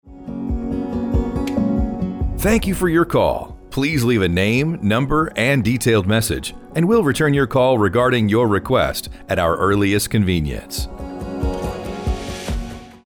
Custom Voicemail Box